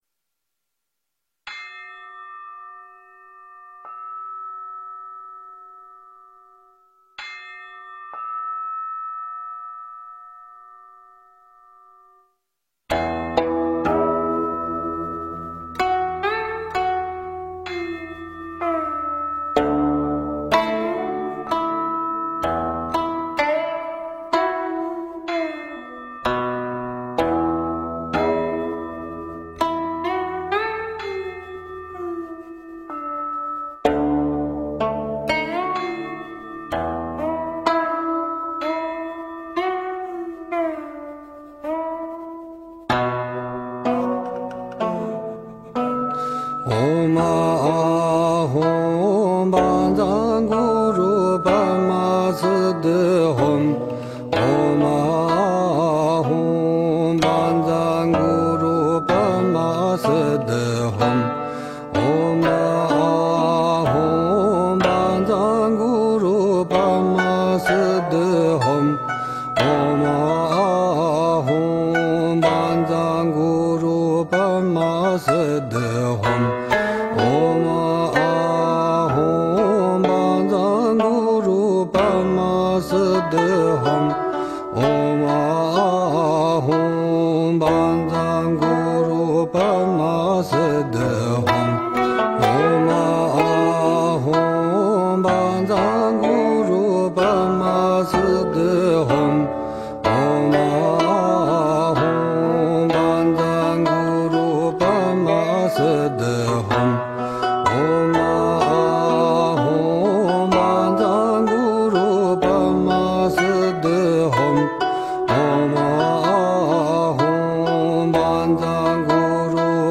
诵经
佛音 诵经 佛教音乐 返回列表 上一篇： 般若波罗蜜多心经(达摩配乐精选